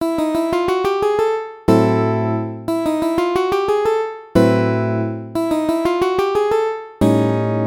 まずはジプシージャズでよく使うアウトロをご紹介します。
これはMinor swingで使われるアウトロですが、それ以外の曲でも全然使います。
ジプシージャズ　アウトロ　作り方
gypsy-jazz-outro.mp3